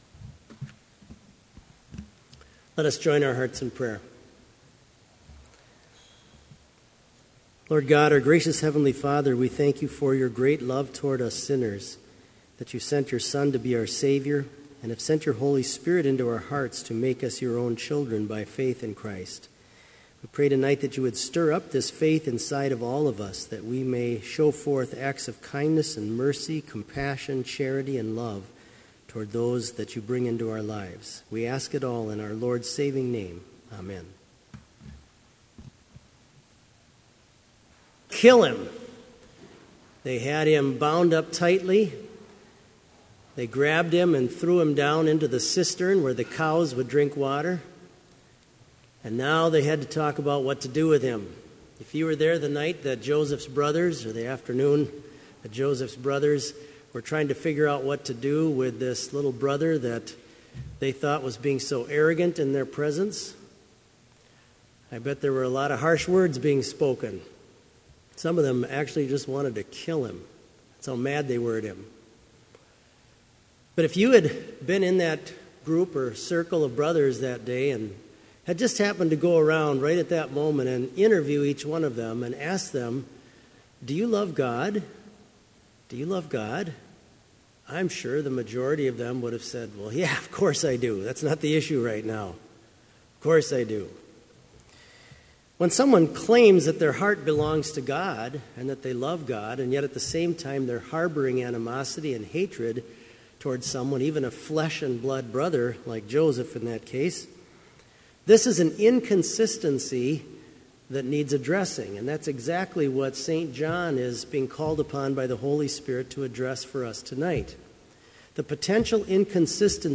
Sermon Only
This Vespers Service was held in Trinity Chapel at Bethany Lutheran College on Wednesday, September 2, 2015, at 5:30 p.m. Page and hymn numbers are from the Evangelical Lutheran Hymnary.